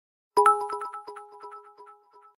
fart_i5GMRdm.mp3